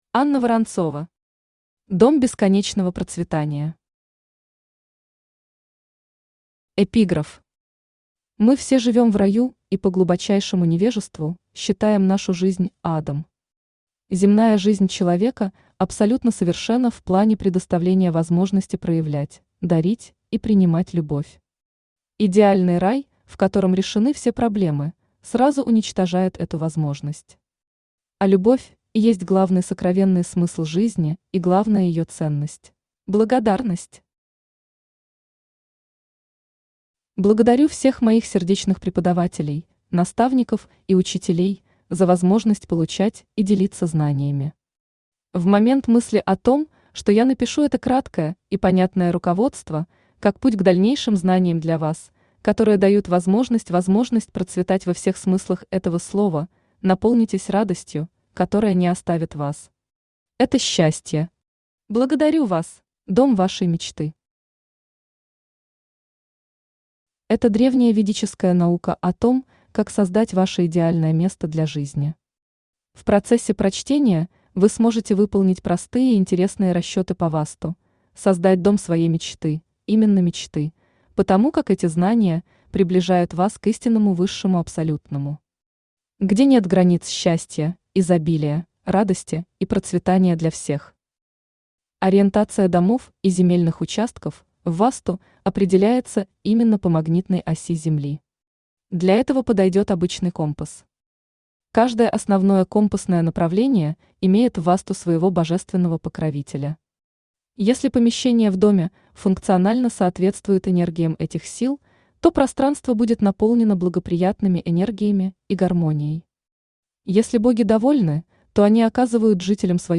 Аудиокнига Дом бесконечного процветания | Библиотека аудиокниг
Aудиокнига Дом бесконечного процветания Автор Анна Борисовна Воронцова Читает аудиокнигу Авточтец ЛитРес.